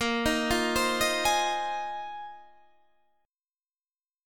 A#7sus2sus4 chord